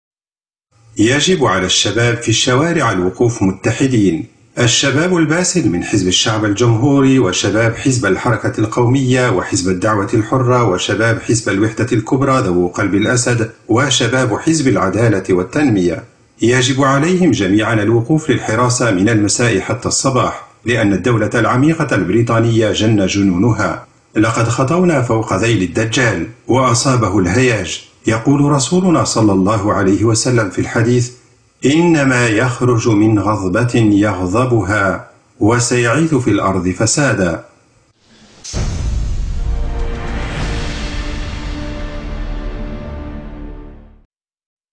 البث المباشر لحوار عدنان أوكطار على قناة A9TV بتاريخ 15 يوليو 2016 عدنان أوكطار: يجب على الشباب في الشوارع الوقوف متحدين.